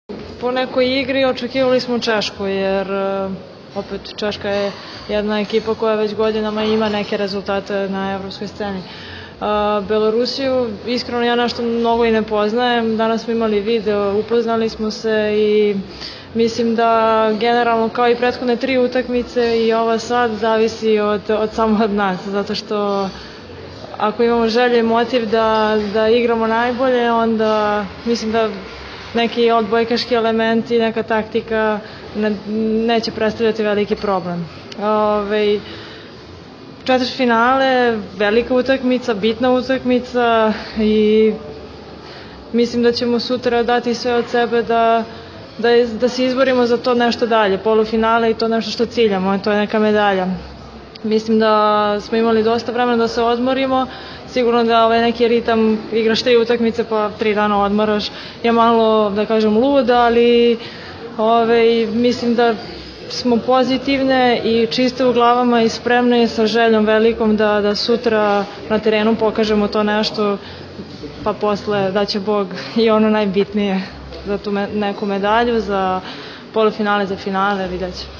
IZJAVA JELENE BLAGOJEVIĆ